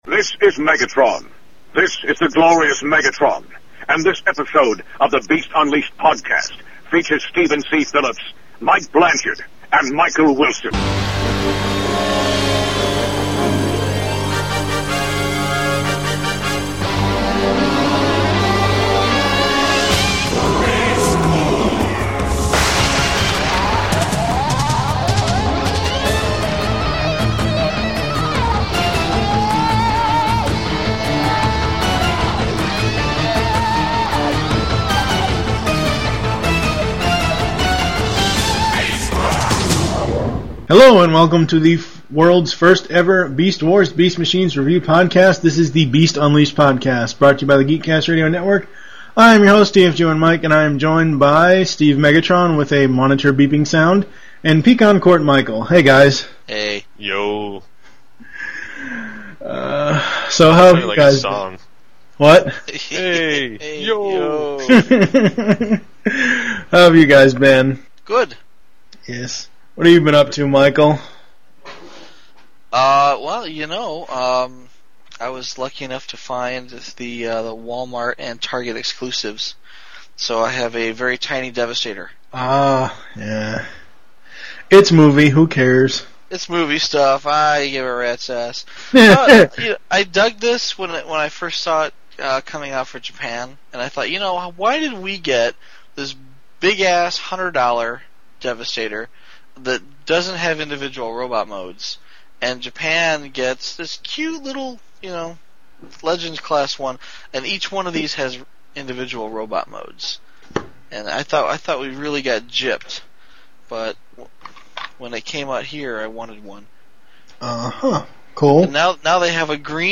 Only 4 episodes into the lifespan of TBU and the three hosts are at it again.